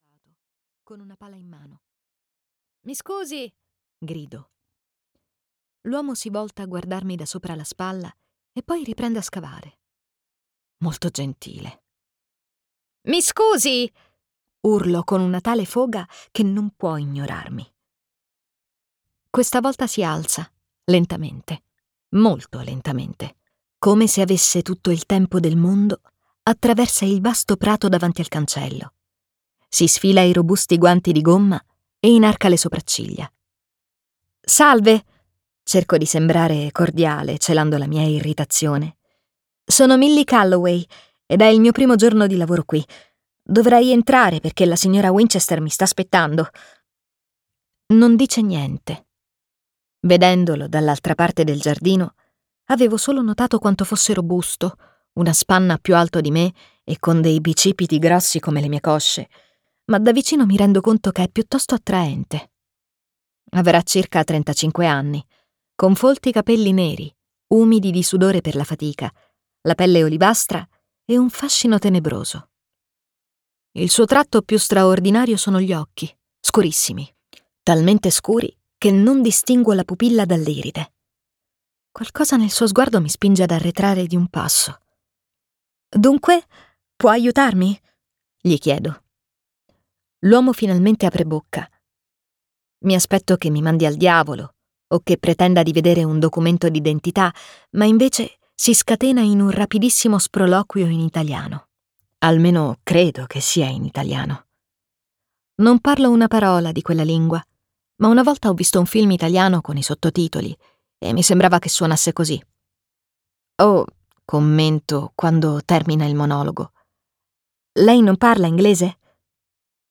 "Una di famiglia" di Freida McFadden - Audiolibro digitale - AUDIOLIBRI LIQUIDI - Il Libraio